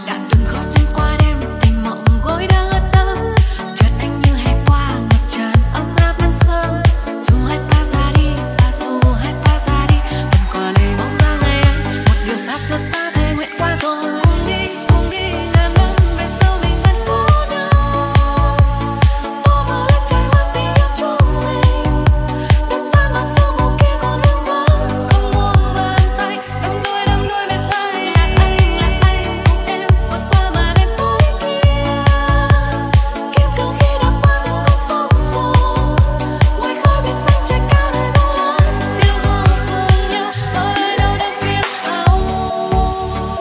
hấp dẫn, nhẹ nhàng, dịu dàng, độc đáo